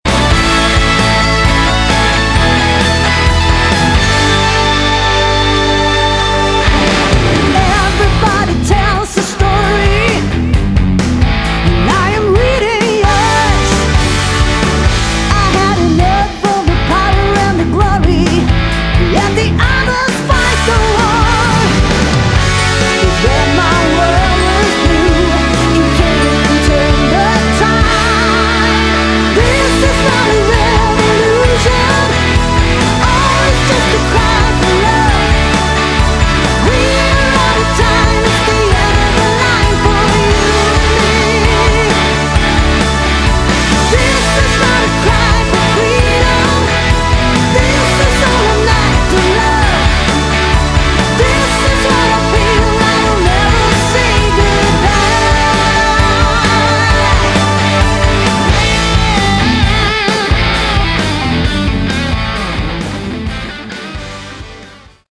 Category: Hard Rock
vocals
guitars
bass guitar
drums